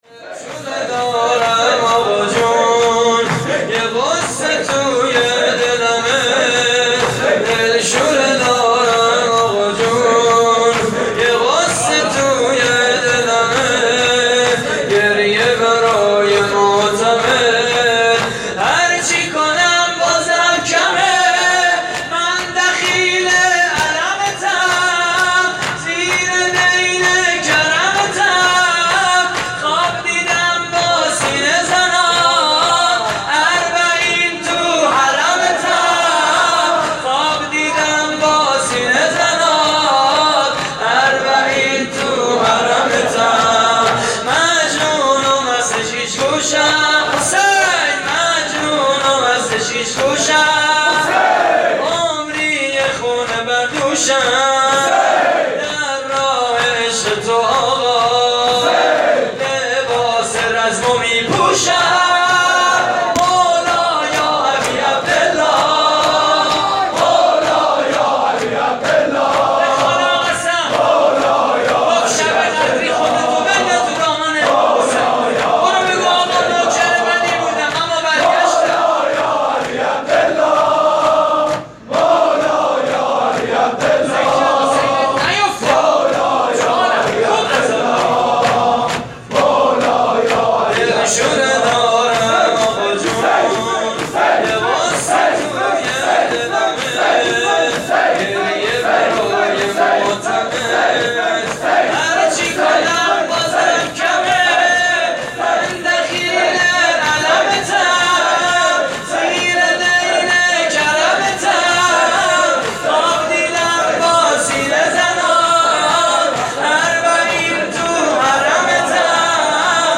مداحی شب 21 رمضان (عزاداری و احیای شب قدر) / هیئت کریم آل طاها (ع)؛ 26 خرداد 96
صوت مراسم:
شور: مولا یا ابی عبدالله